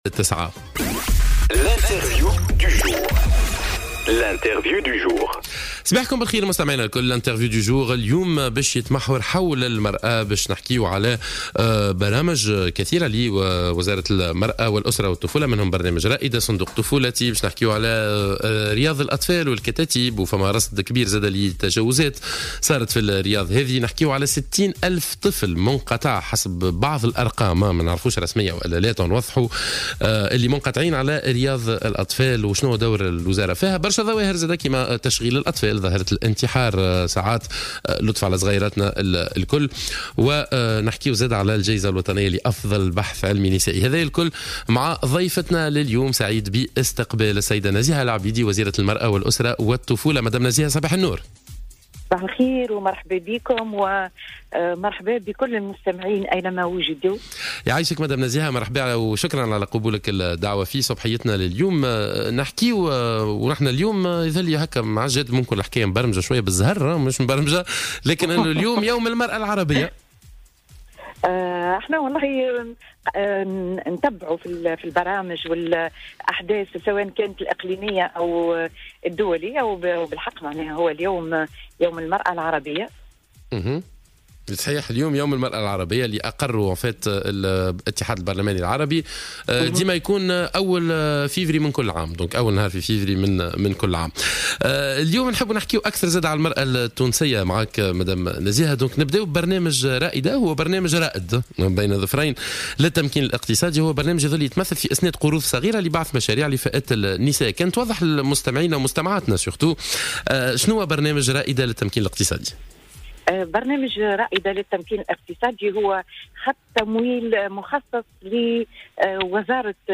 وأوضحت الوزيرة لـ "الجوهرة اف أم" على برنامج "صباح الورد" أن هذه القروض هي عبارة عن خط تمويل مخصص لوزارة المرأة بالتعاون مع بنك التضامن لفائدة النساء في كامل تراب الجمهورية وعلى كل مواطنة ترغب في هذا التمويل الاتصال بالمندوبية الجهوية للوزارة في جميع الولايات لتقديم مطالبهنّ.